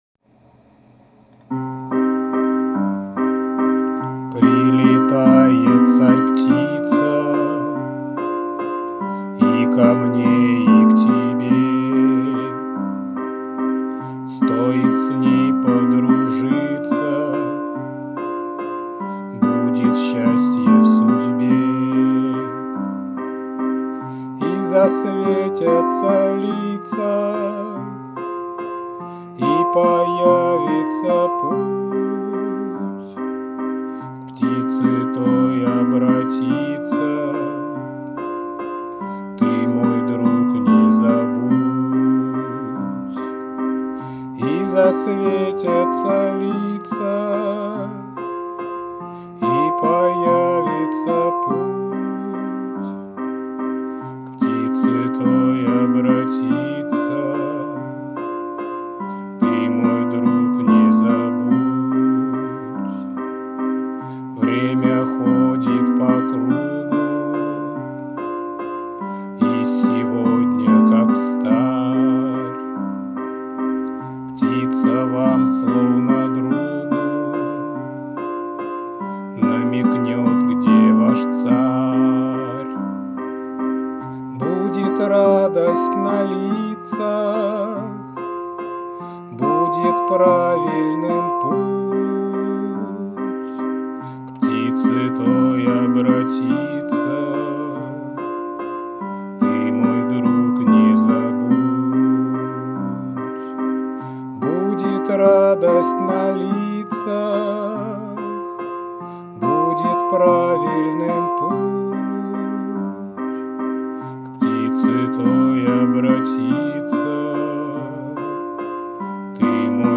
• Жанр: Детская